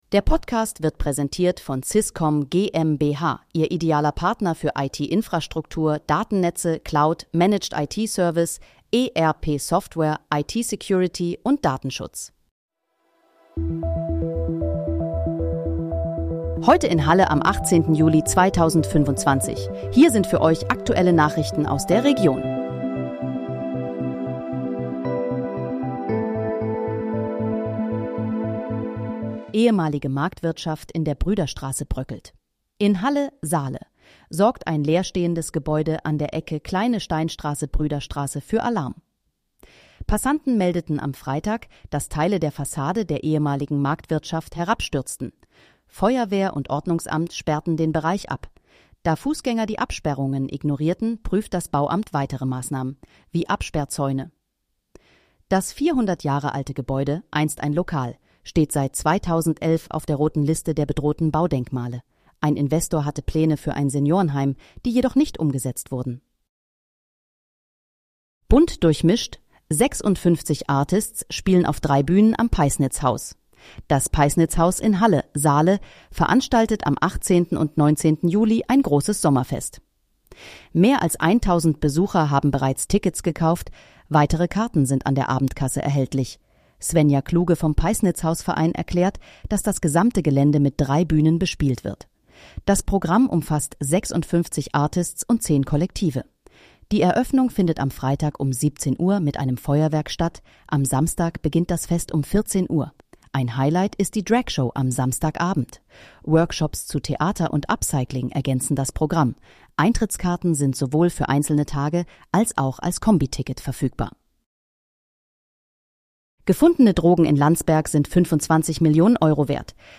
Heute in, Halle: Aktuelle Nachrichten vom 18.07.2025, erstellt mit KI-Unterstützung
Nachrichten